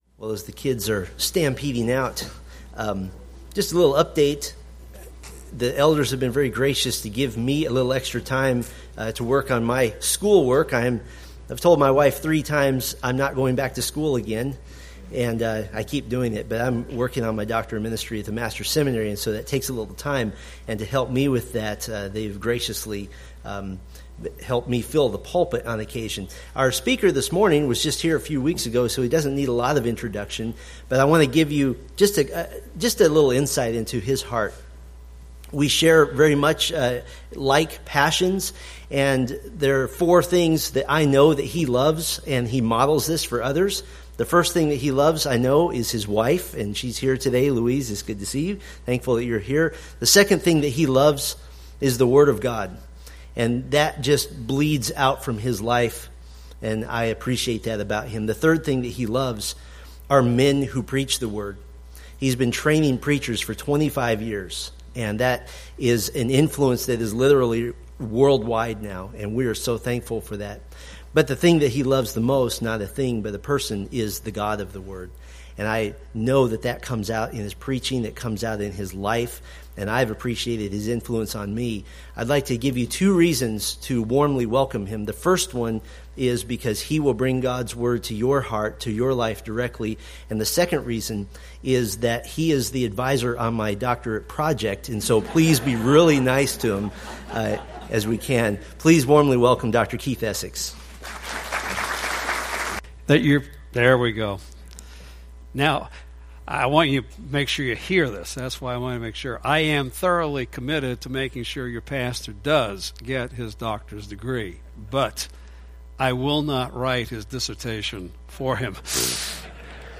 Preached November 20, 2016 from Luke 10:25-37